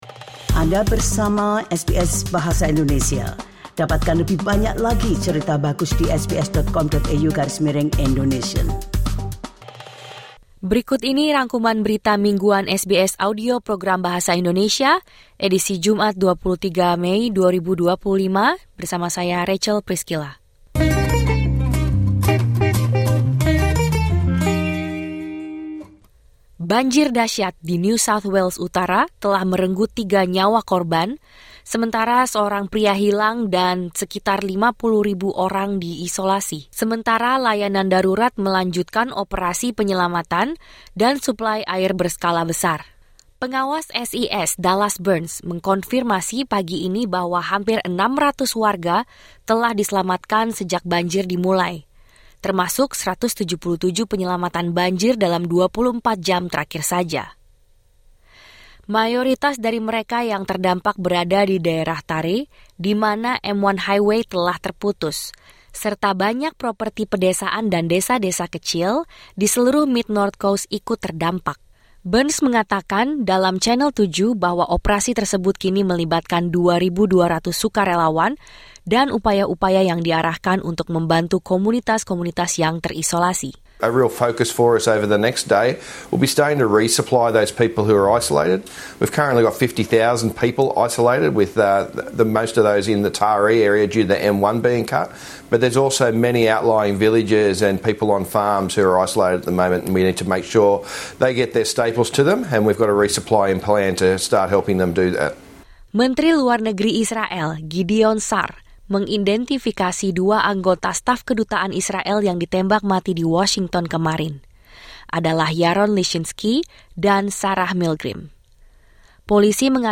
Rangkuman Berita Mingguan SBS Audio Program Bahasa Indonesia - 23 Mei 2025